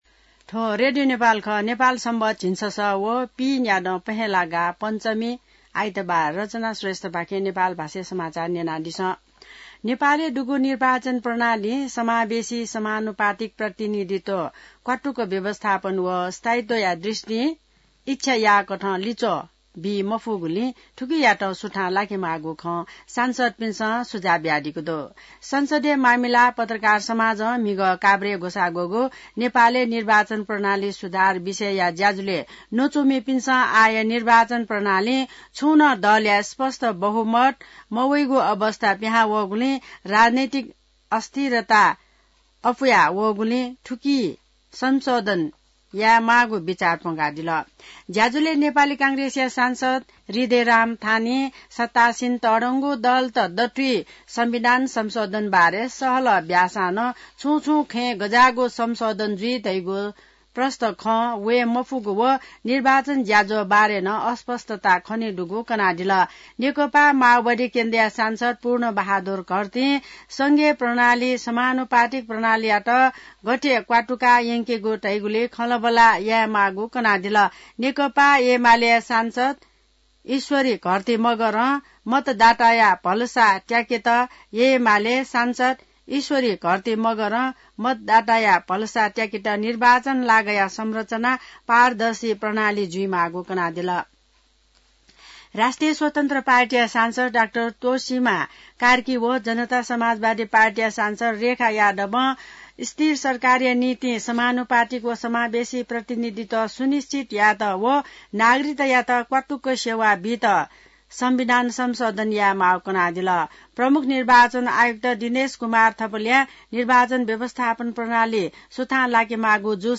नेपाल भाषामा समाचार : ७ माघ , २०८१